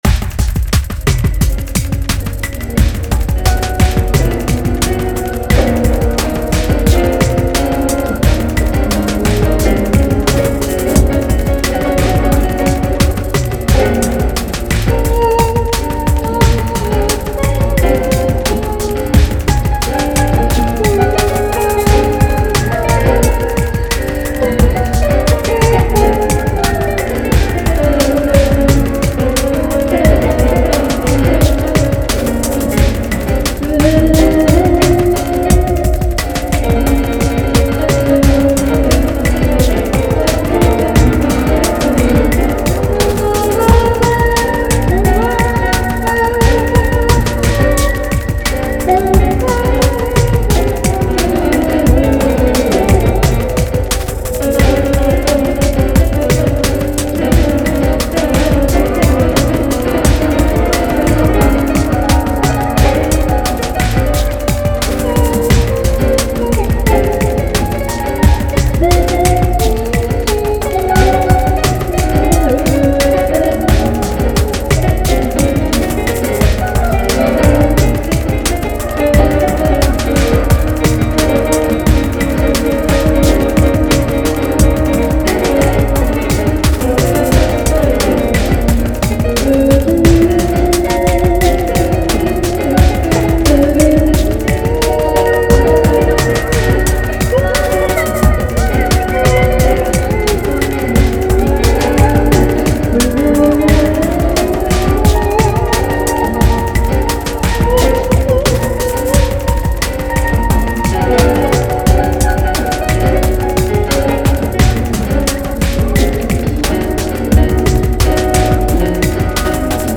piano jouet, effet psychédélique, enregistrement avec cellulaire